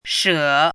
怎么读
shě
she3.mp3